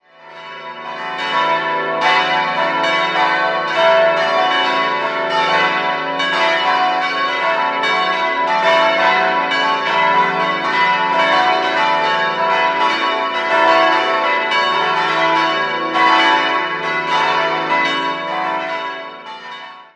Martin-Luther-Glocke e'+2 930 kg 1959 Karl Czudnochowsky, Erding (Bronze) Paul-Gerhardt-Glocke fis'+0 657 kg 1956 Karl Czudnochowsky, Erding (Euphon) Johann-Sebastian-Bach-Glocke a'+2 394 kg 1956 Karl Czudnochowsky, Erding (Euphon) Wilhelm-Löhe-Glocke h'+2 298 kg 1959 Karl Czudnochowsky, Erding (Bronze) Heinrich-Schütz-Glocke cis''+2 187 kg 1956 Karl Czudnochowsky, Erding (Euphon)